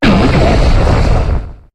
Cri de Démétéros dans Pokémon HOME.